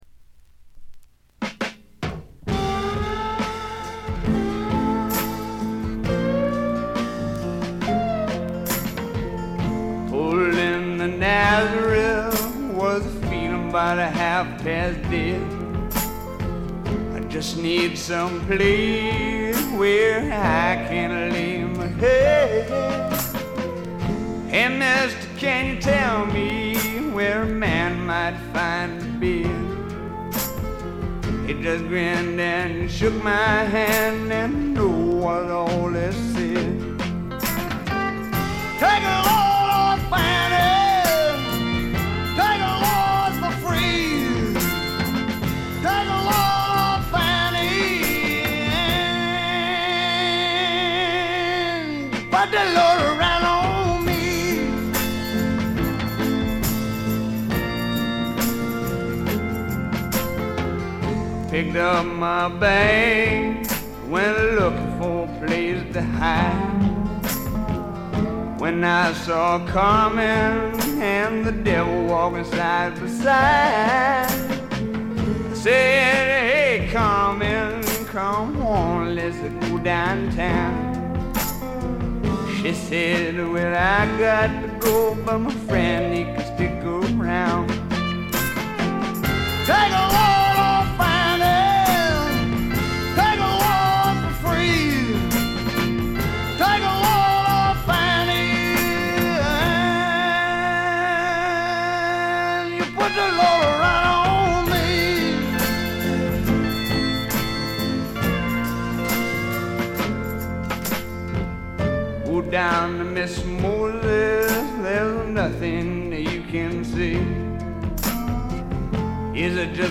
チリプチ少々。目立つノイズはありません。
60年代的なポップな味付けを施しながらも、ねばねばなヴォーカルがスワンプど真ん中の直球勝負で決めてくれます。
試聴曲は現品からの取り込み音源です。